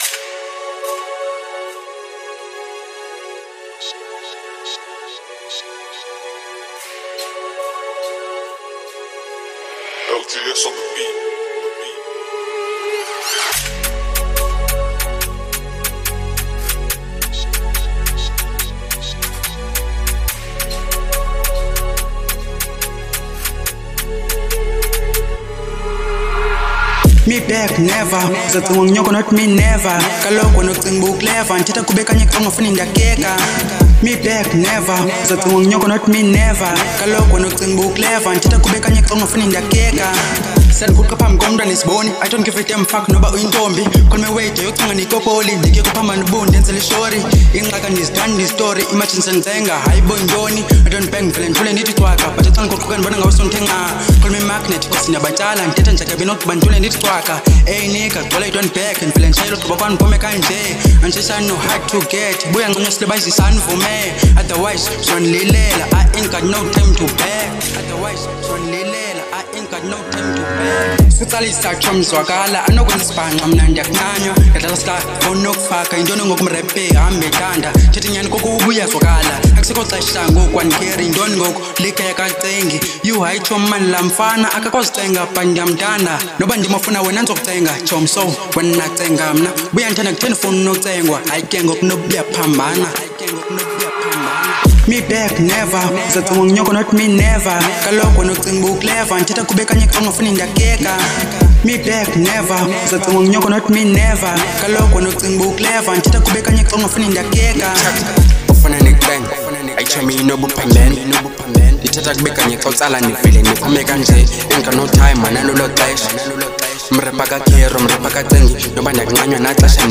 02:59 Genre : Hip Hop Size